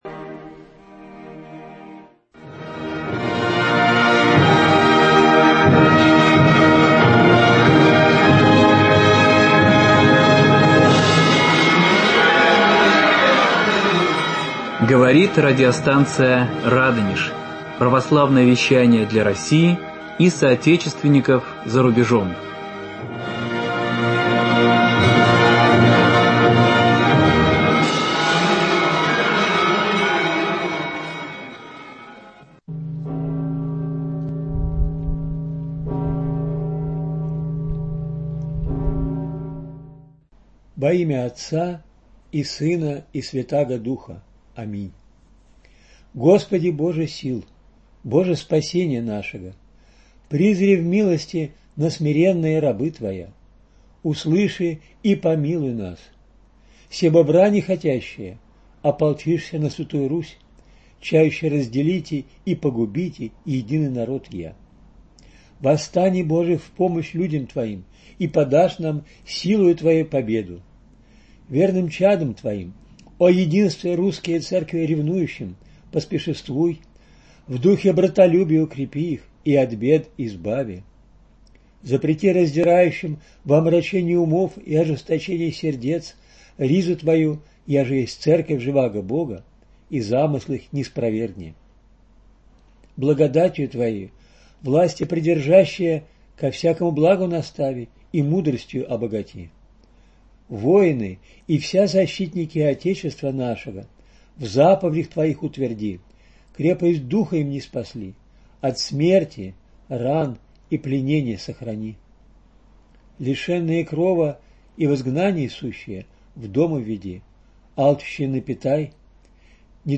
В новых передачах радиоцикла знакомим слушателей с особенностями становления в России накануне Первой Мировой войны всего комплекса авиационного дела, с появлением целого ряда необычных для того времени профессий - воздухоплавателя и авиатора, военного летчика, авиаконструктора. На вопросы ведущей отвечают историк авиации